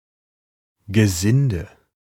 Ääntäminen
Etsitylle sanalle löytyi useampi kirjoitusasu: hands Hands Ääntäminen US : IPA : [hændz] Haettu sana löytyi näillä lähdekielillä: englanti Käännös Konteksti Ääninäyte Substantiivit 1.